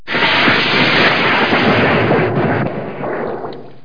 torplnch.mp3